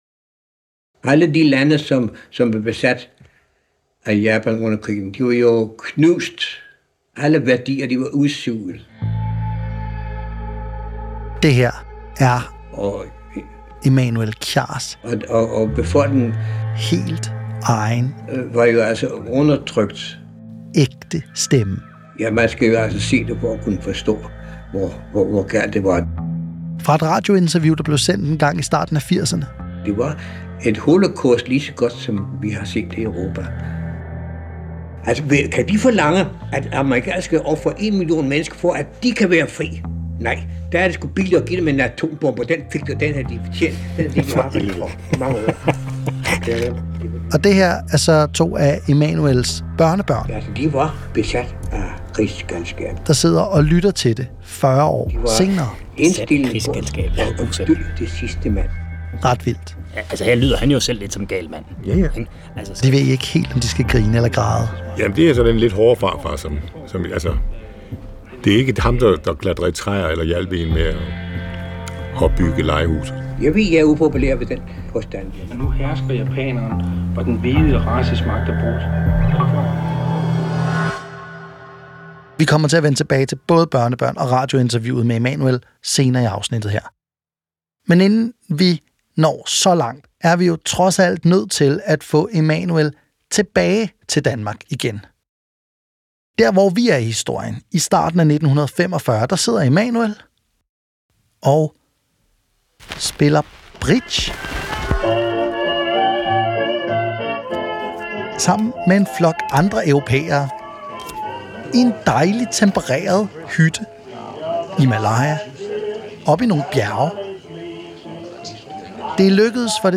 De Lovløse Author: DR Language: da Genres: Documentary , History , Society & Culture Contact email: Get it Feed URL: Get it iTunes ID: Get it Get all podcast data Listen Now...